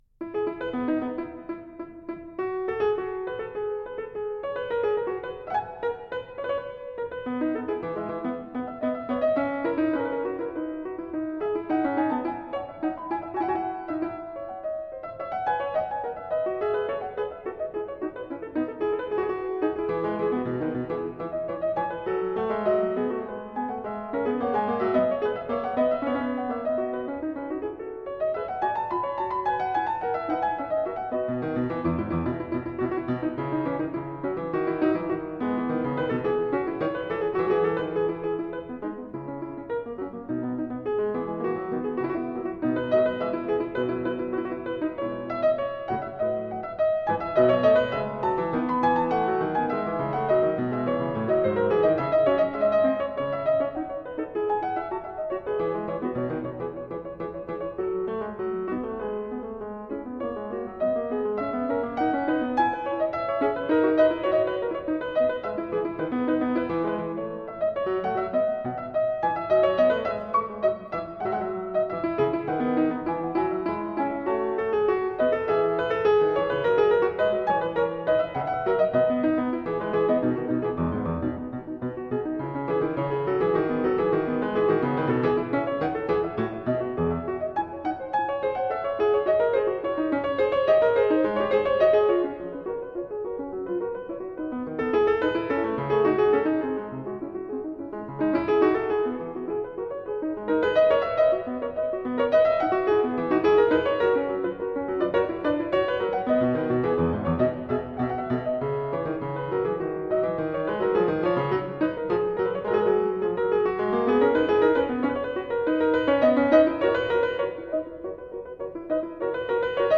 Group: Instrumental